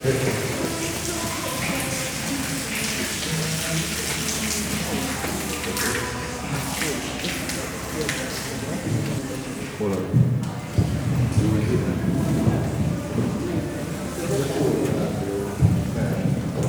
Directory Listing of /_MP3/allathangok/szegedizoo2011_standardt/zebra/
aszallasonvizel00.16.wav